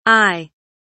eye kelimesinin anlamı, resimli anlatımı ve sesli okunuşu